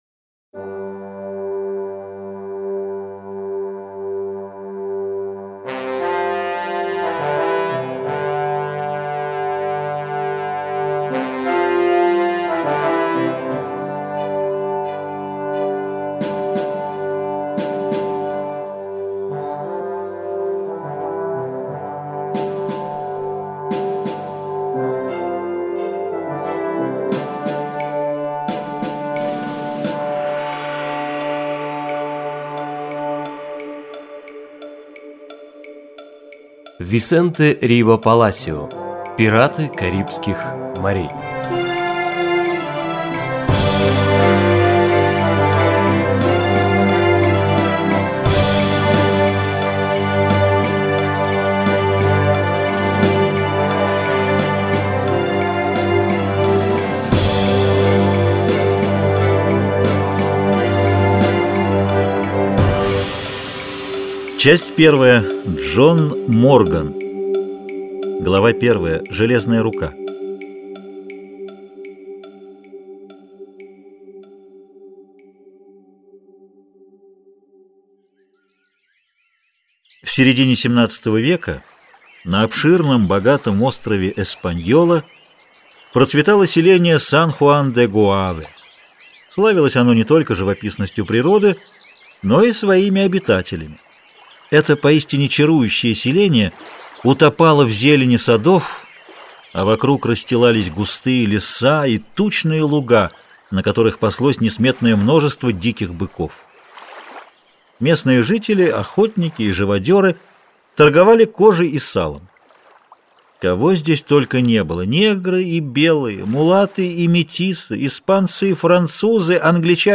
Аудиокнига Пираты Карибских Морей | Библиотека аудиокниг